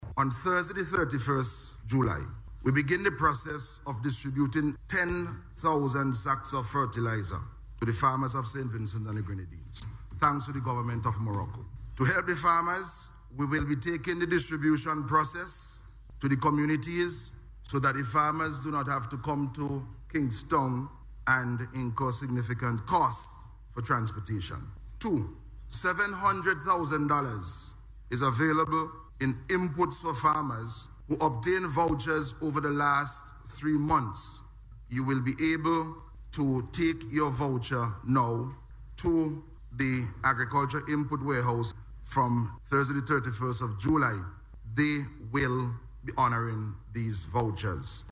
Minister of Agriculture, Saboto Caesar made this announcement during a Ministerial Statement in Parliament on Monday.